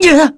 Theo-Vox_Damage_kr_02.wav